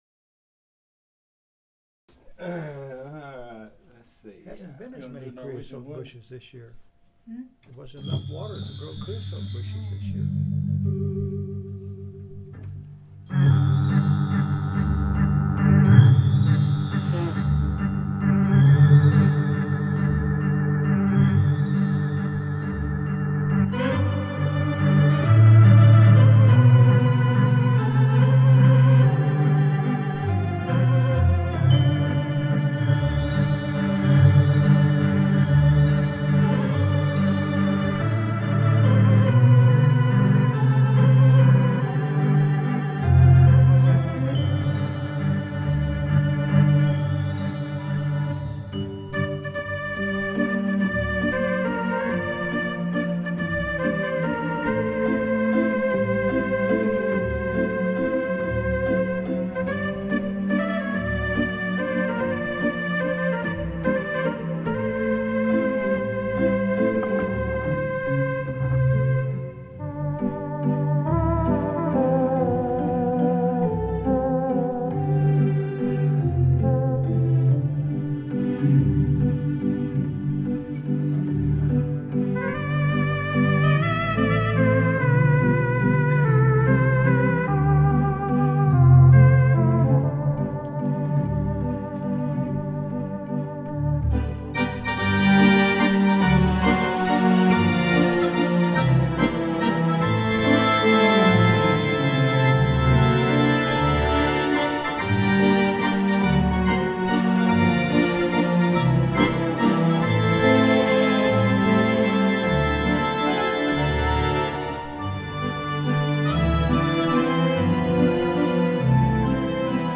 Roland Atelier AT90-SL Digital Residence Organ.
In some of the tracks, listed below, you can hear cups being sat down on the table.
Also, since this is totally unrehearsed music, there are a number of mistakes, but hey, it's live and impromptue.